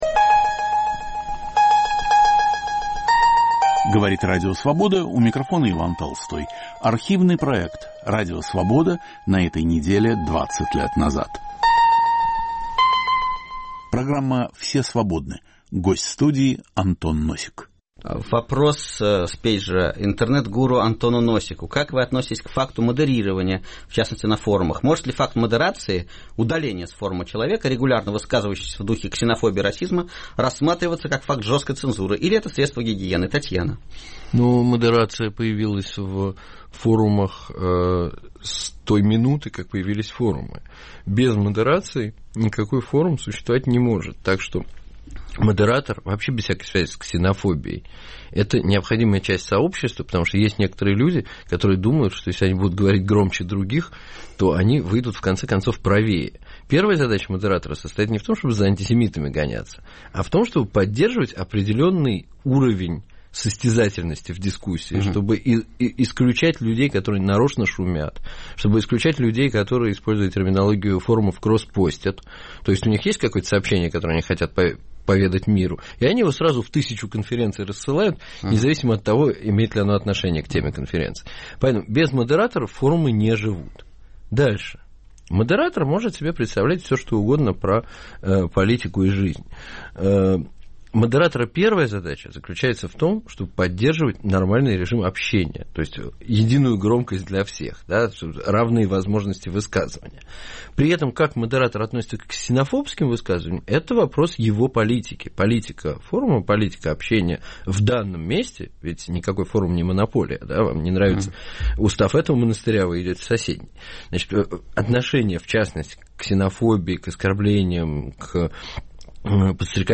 Знаковая фигура русского интернета в студии Радио Свобода. Автор и ведущий Виктор Шендерович.